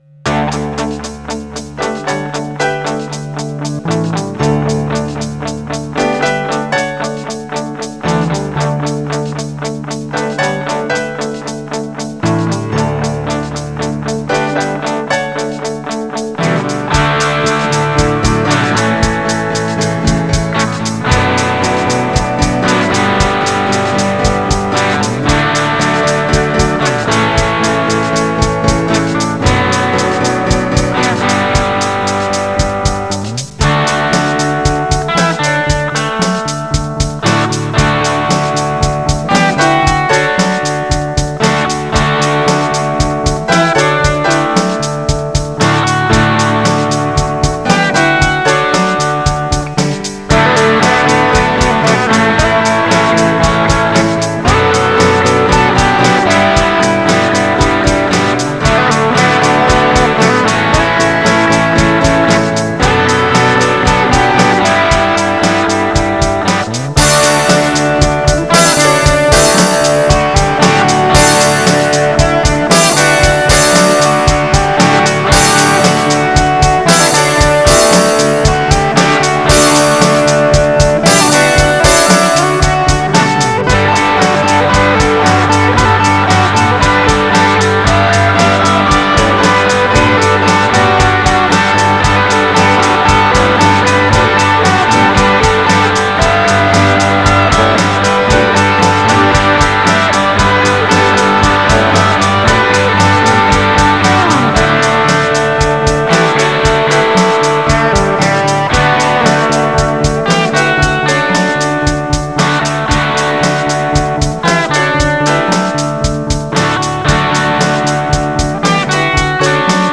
Monday is Guitar Day
The best way to celebrate life’s little triumphs is to post your mediocre guitar playing, amiright?
I claim copyright anyway, so there; 3. No, I cannot do anything about the general quality of the mix, as I am incompetent.